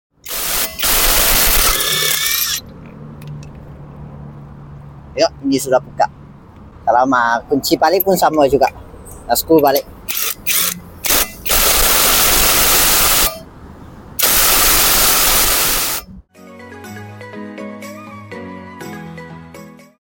LANLONG 21V 4.0Ah Cordless Impact Wrench.